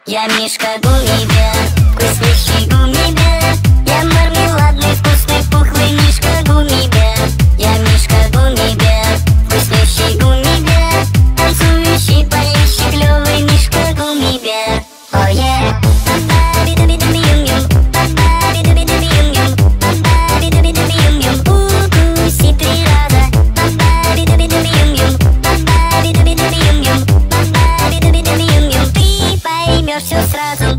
веселые
забавный голос